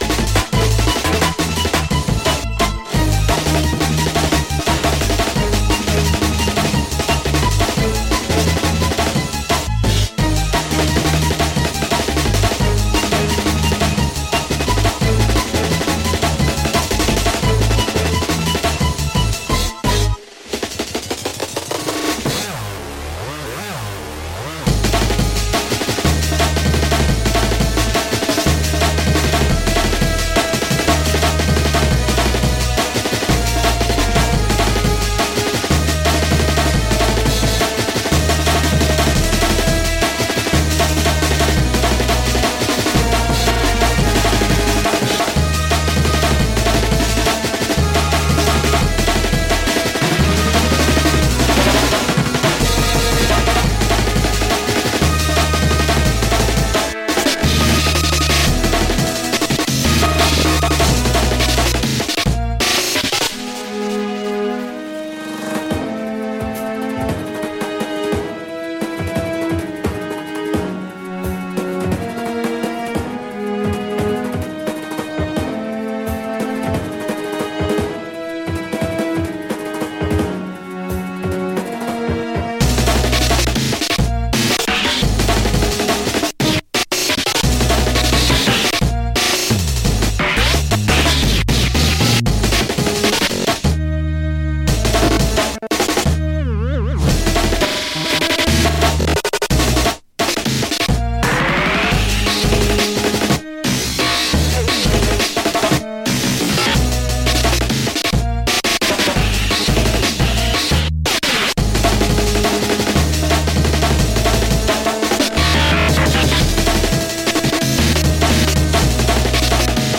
Amen breaks como nunca os ouvimos